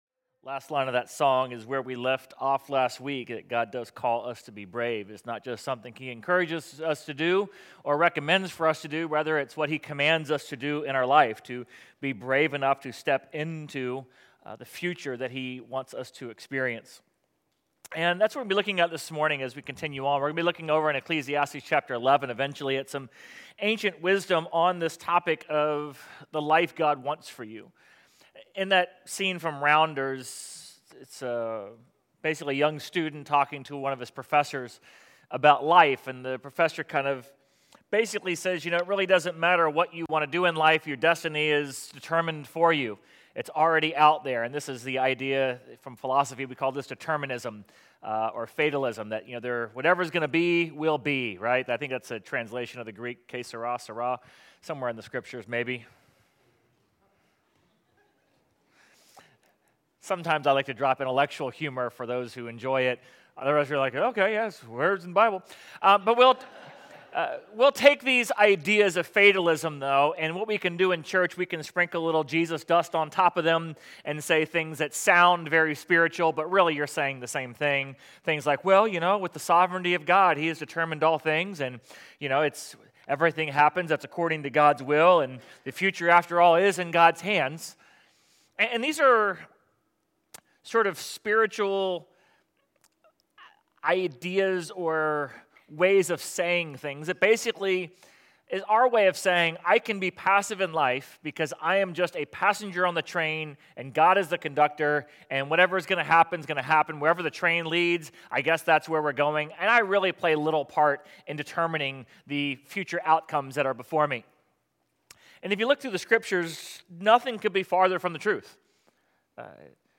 Sermon_8.17.25.mp3